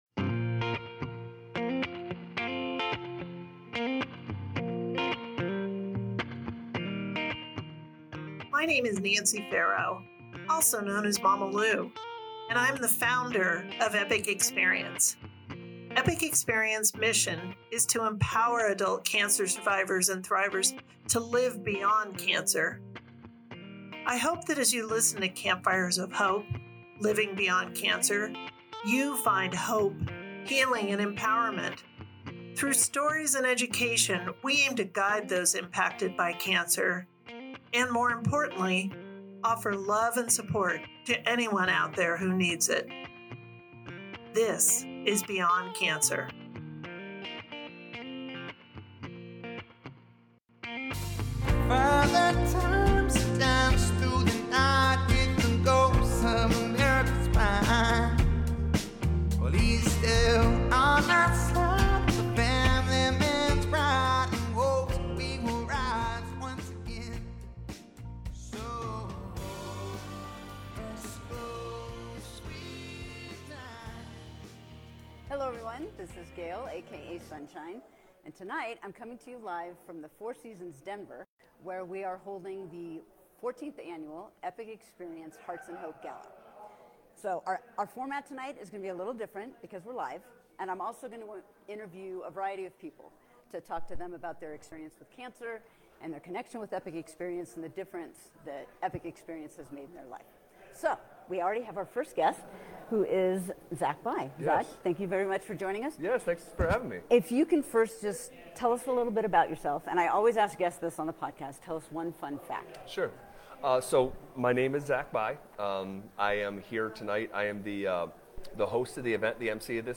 Live from the Hearts & Hope Gala (Part 1)